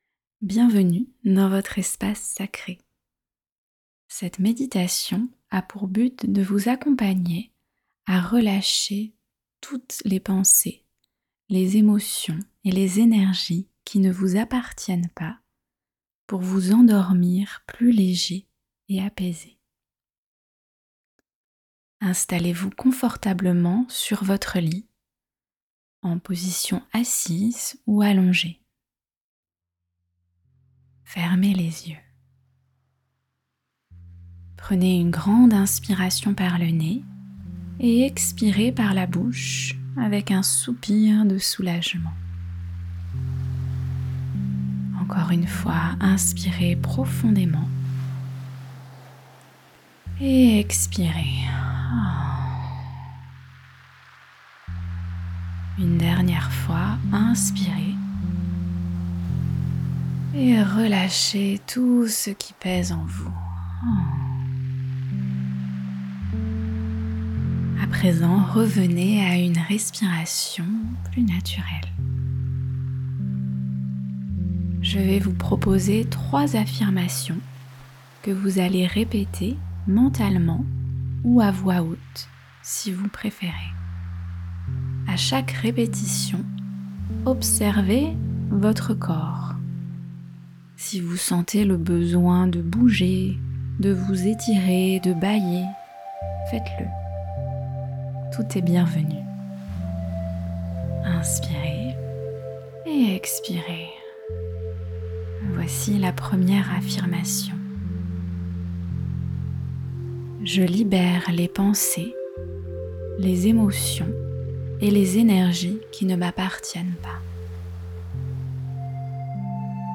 MÉDITATION GUIDÉE | S’endormir léger et apaisé
meditation-guidee-sendormir-leger-et-apaise.mp3